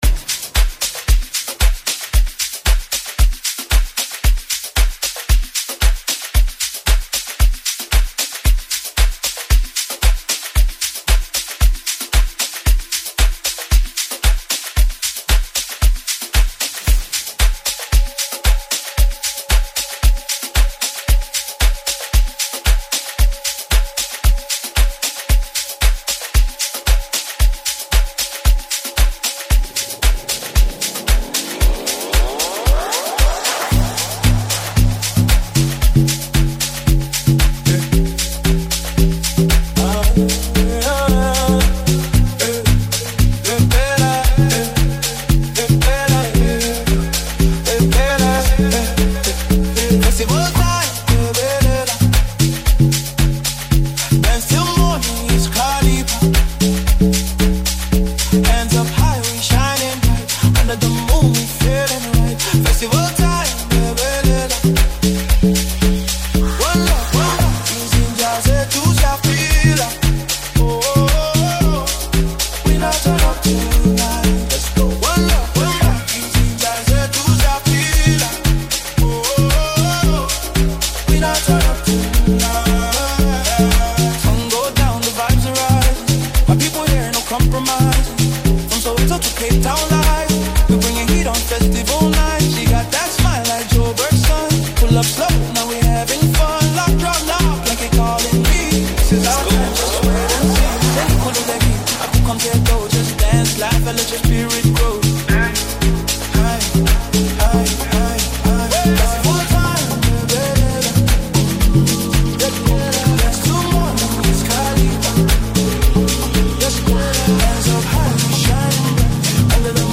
AMAPIANO SONGS